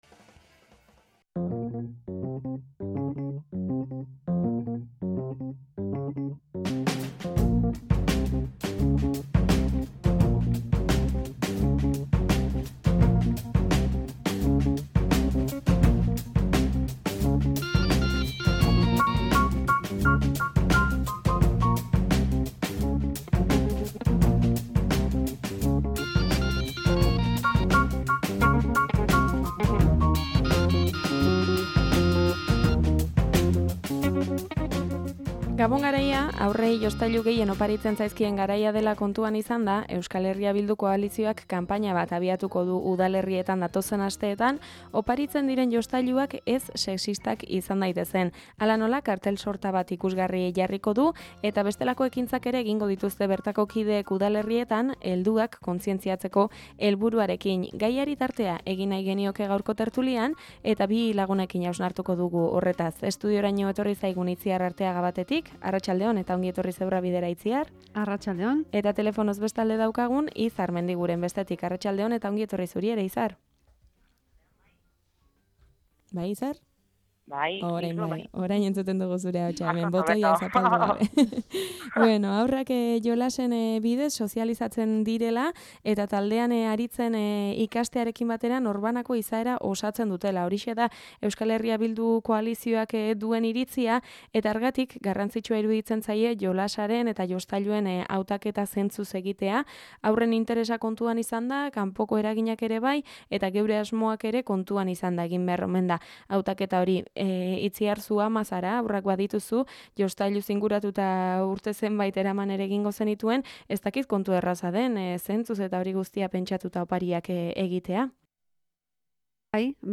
TERTULIA: haurrentzako jostailuek izaeraren eraikuntzan duten eragina